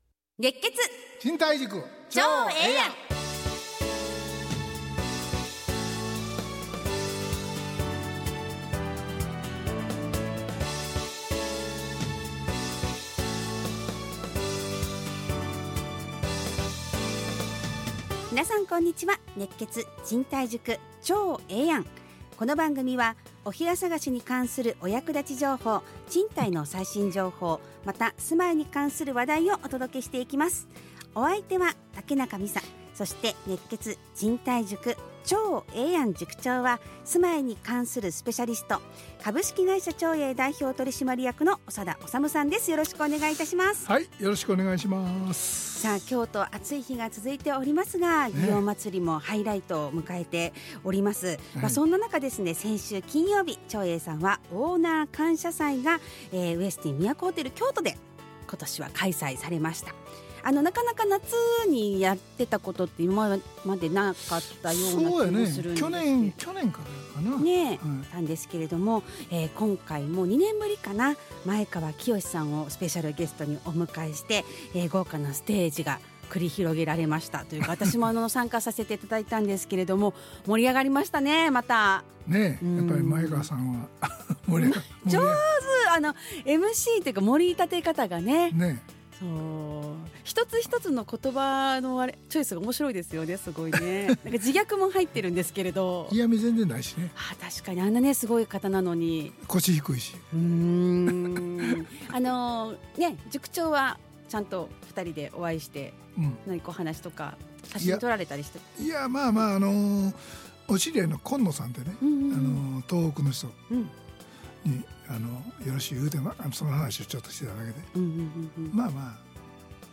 ラジオ放送 2025-07-18 熱血！